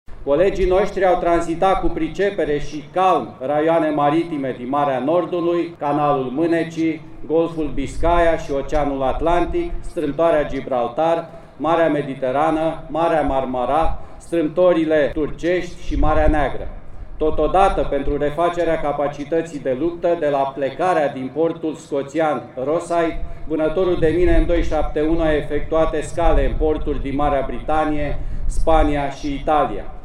Șeful Statului Major al Forțelor Navale, viceamiral Mihai Panait: